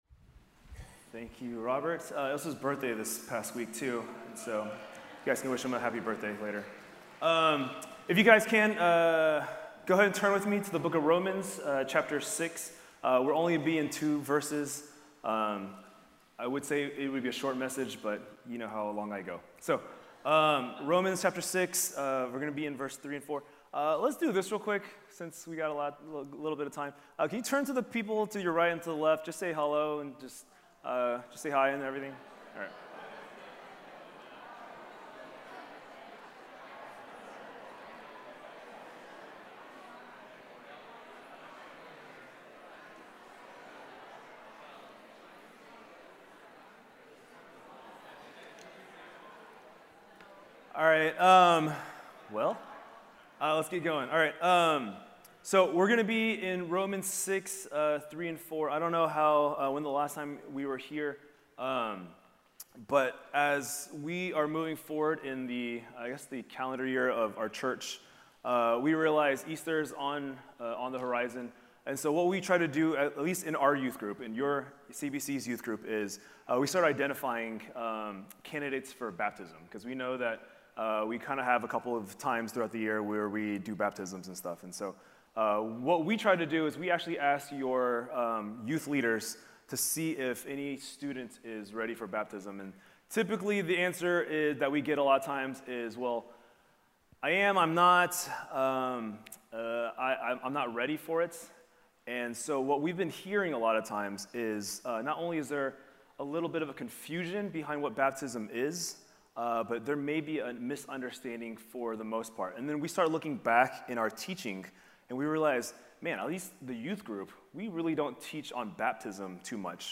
English Sermons